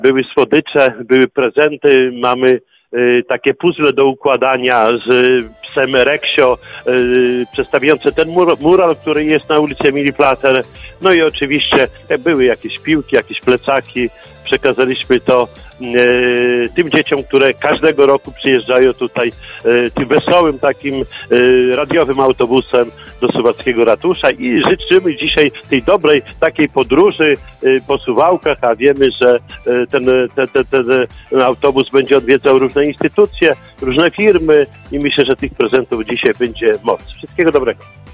Pierwszy na trasie był miejscowy Ratusz. Dzieci powitał Czesław Renkiewicz, prezydent Suwałk.